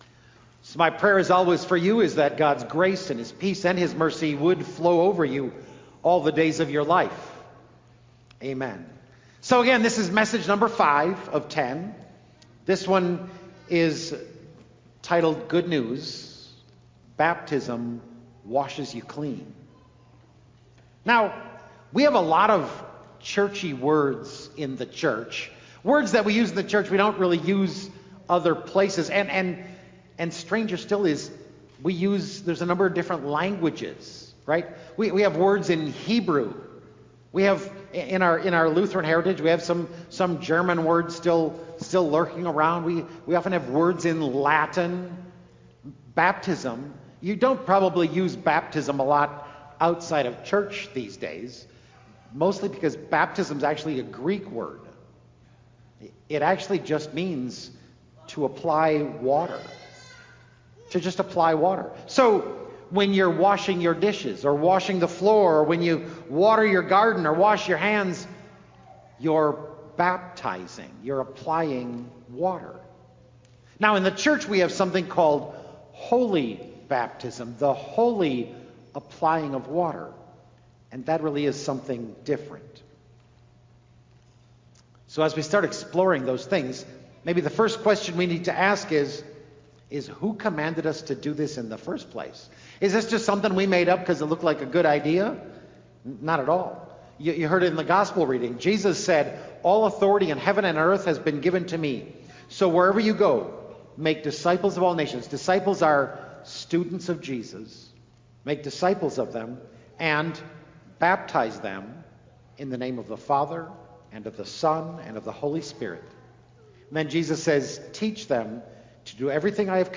Sermon Audio
07-22-Good-News-Baptism-Washes-You-Clean-Sermon-Audio-CD.mp3